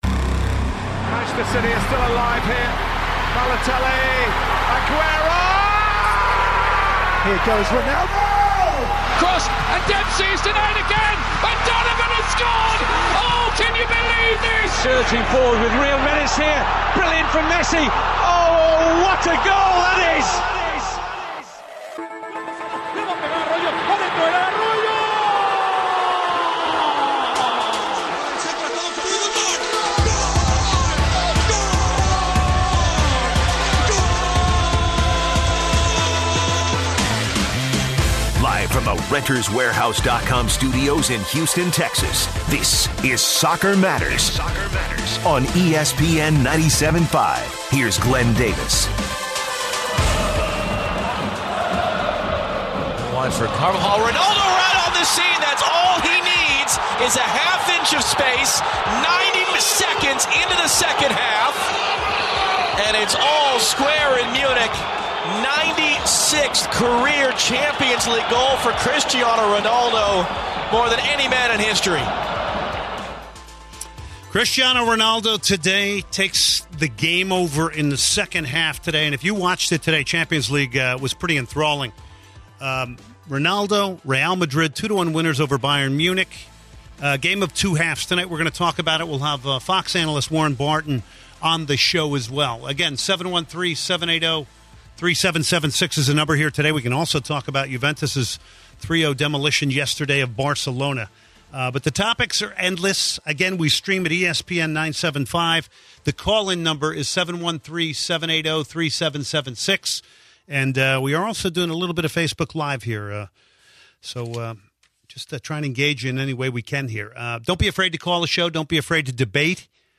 Also, Warren Barton comes on to talk about the UCL activity this week and breakdown the quarter-final games. To end the first segment, Andrew Wenger from the Houston Dynamo, comes in-studio to talk about the Houston Dynamo, the chemistry inside the team’s locker room, playing on artificial surface and the style of play that the team is headed towards.